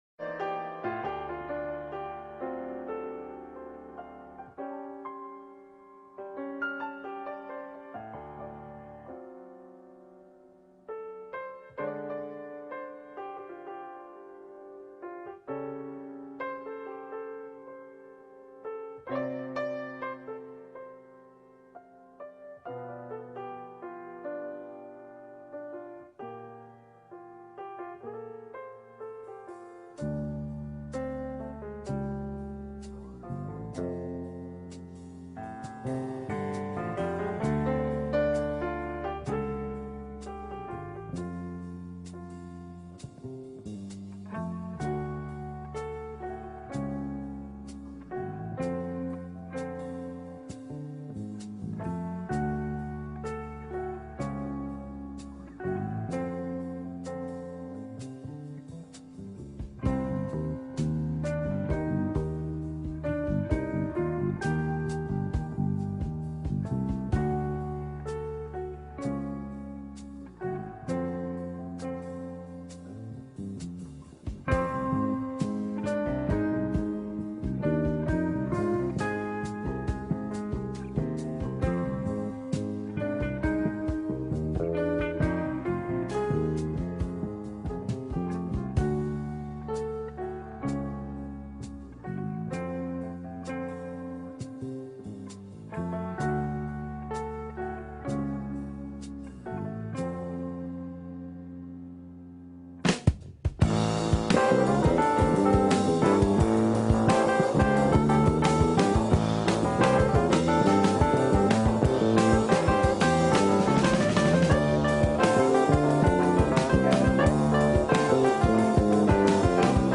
Jazz Fusion